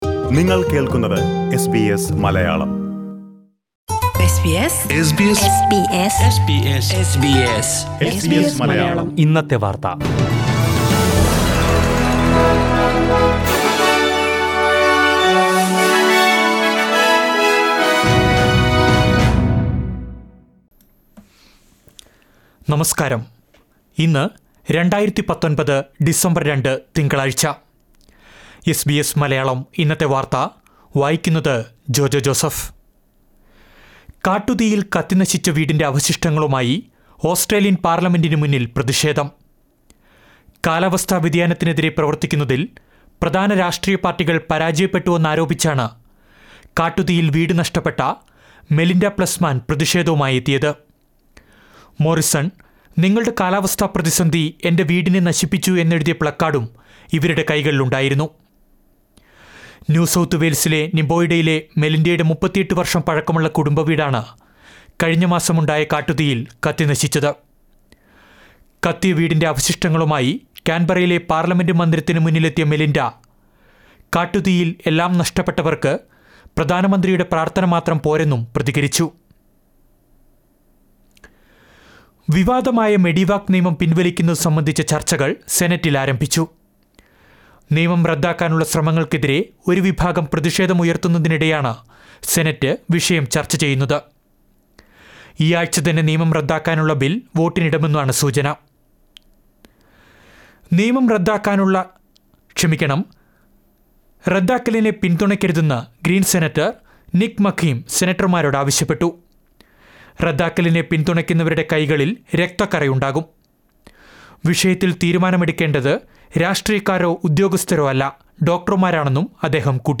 2019 ഡിസംബര്‍ രണ്ടിലെ ഓസ്ട്രേലിയയിലെ ഏറ്റവും പ്രധാന വാര്‍ത്തകള്‍ കേള്‍ക്കാം...